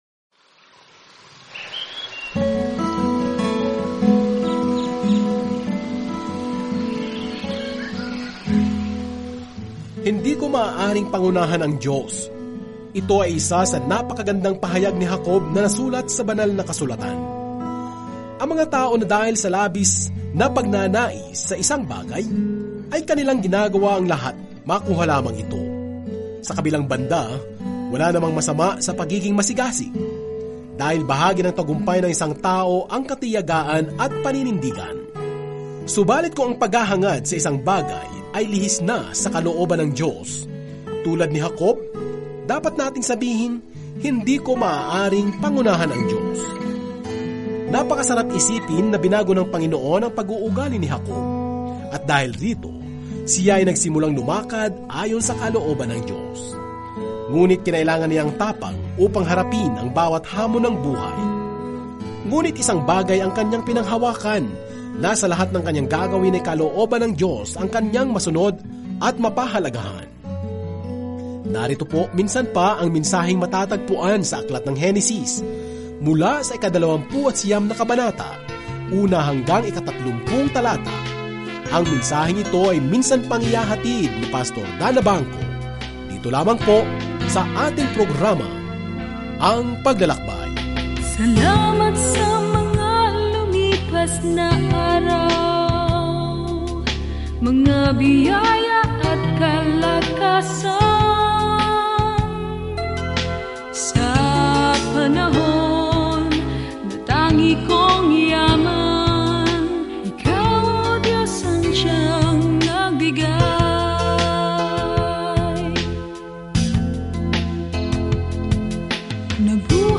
Araw-araw na paglalakbay sa Genesis habang nakikinig ka sa audio study at nagbabasa ng mga piling talata mula sa salita ng Diyos.ere sa aklat ng Genesis.